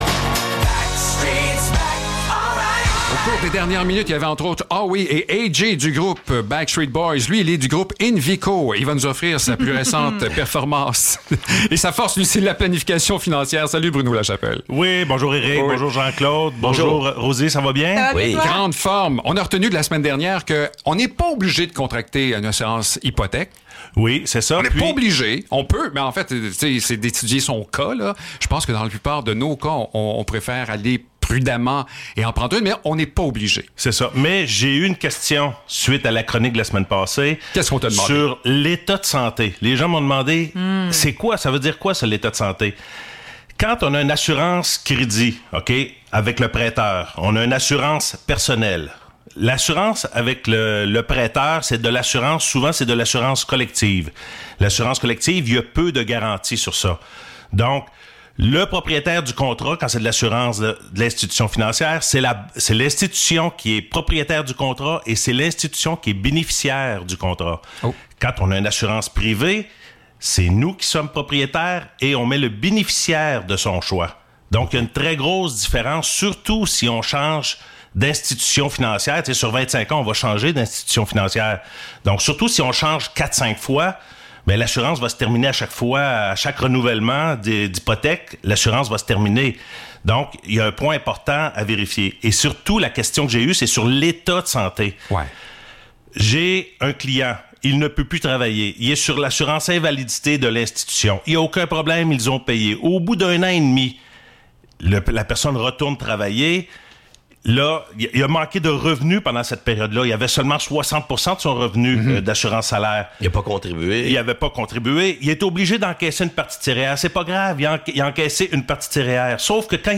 Chronique-Financiere-VIA-90.5-FM.mp3